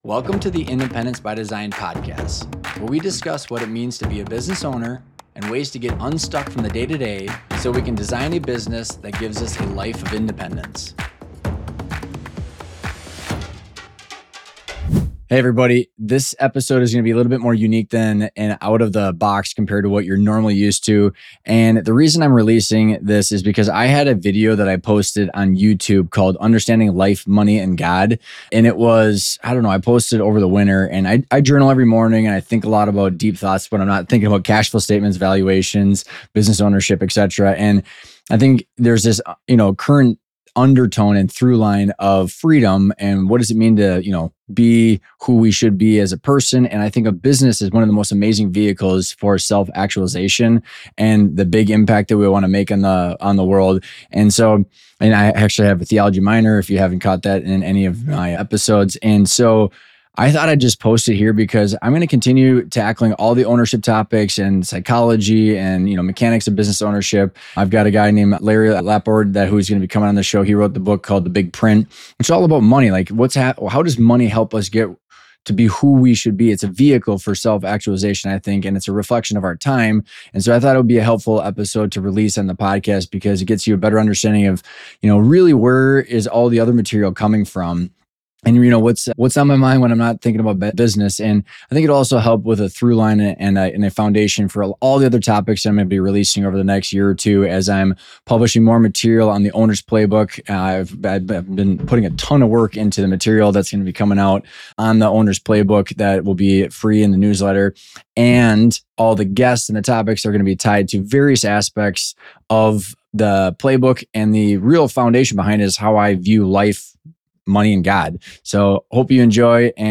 No guest.